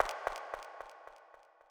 snd_step1.wav